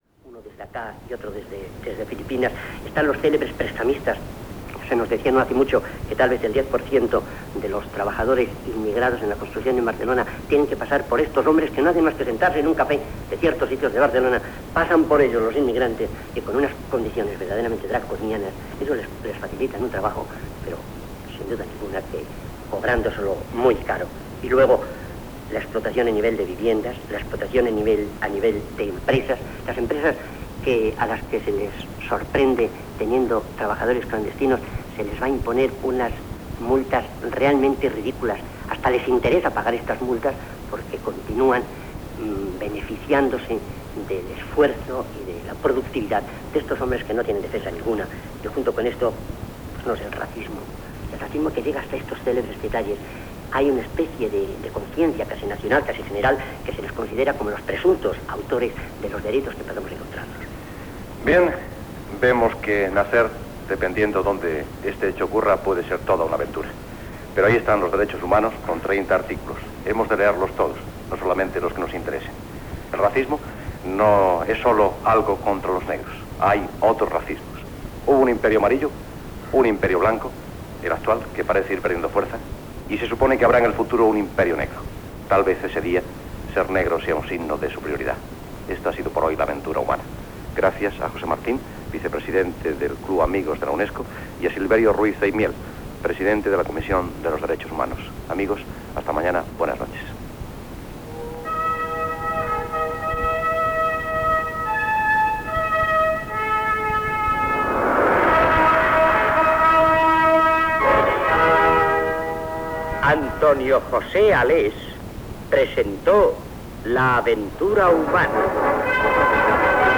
Final de l'espai dedicat al racisme, comiat i careta de sortida
Divulgació